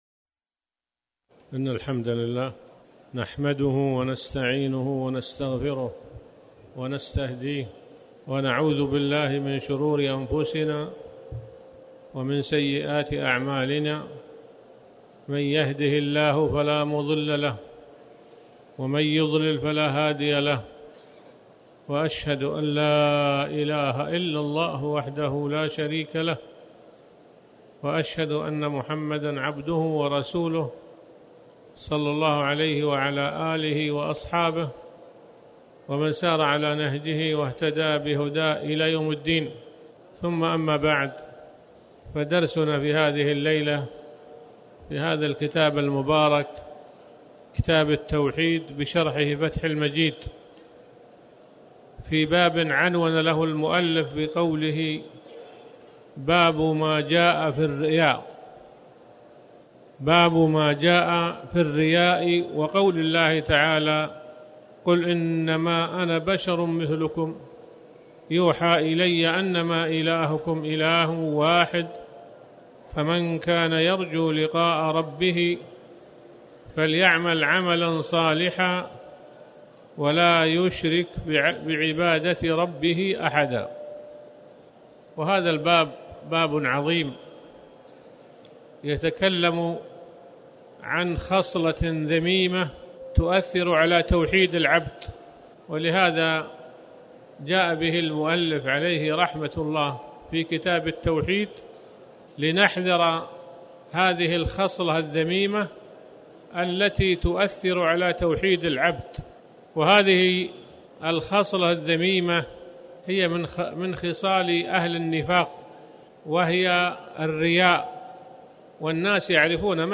تاريخ النشر ٢٥ صفر ١٤٣٩ هـ المكان: المسجد الحرام الشيخ